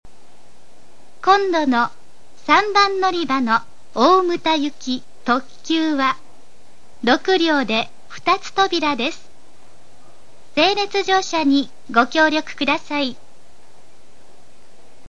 ＜スピーカー＞　天井埋込型
＜曲名（本サイト概要）＞　西鉄主要　／　全線-女性放送
○案内放送（特急・大牟田）